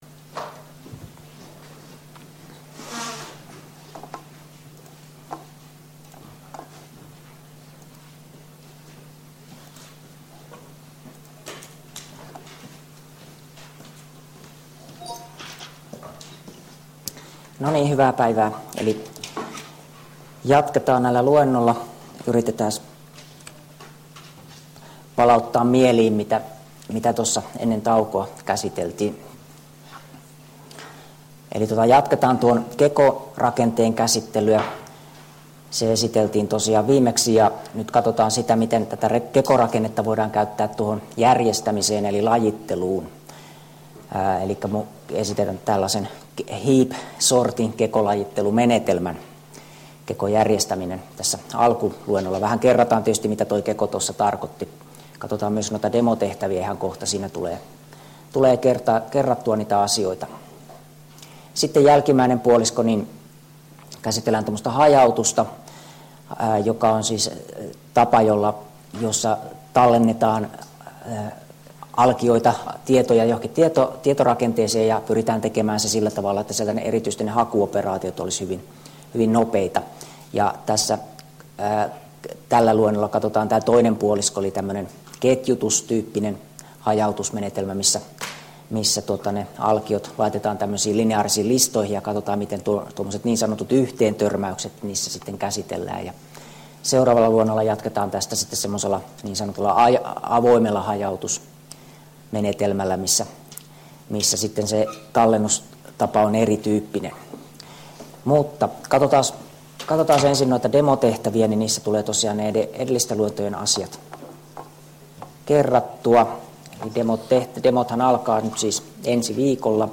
Luento 3 — Moniviestin